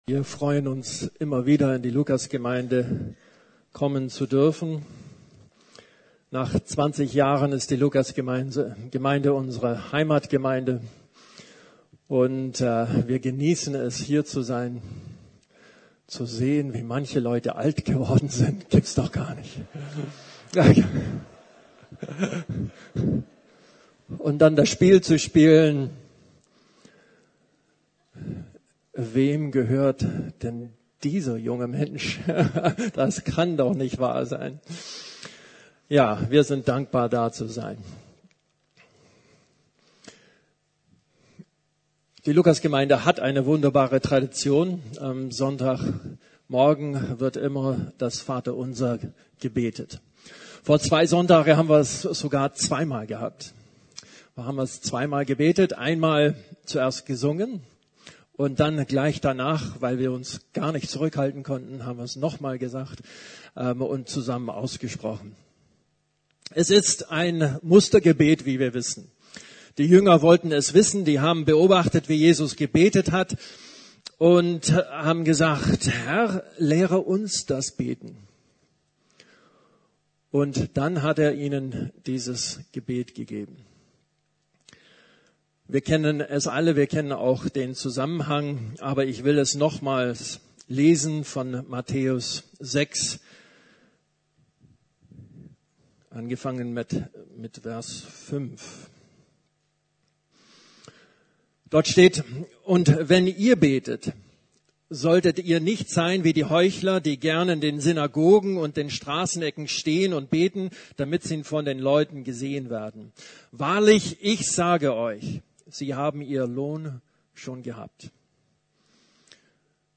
Denn dein ist das Reich und die Kraft und die Herrlichkeit ~ Predigten der LUKAS GEMEINDE Podcast